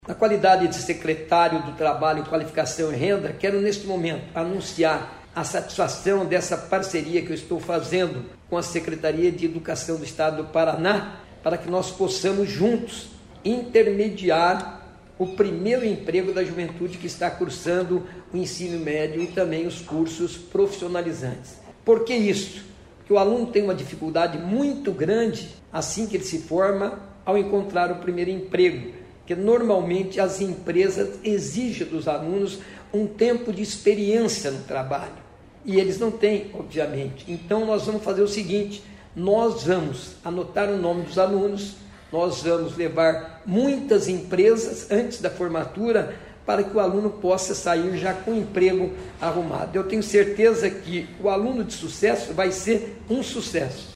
Sonora do secretário do Trabalho, Qualificação e Renda, Mauro Moraes, sobre o projeto Aluno de Sucesso, lançado nesta terça-feira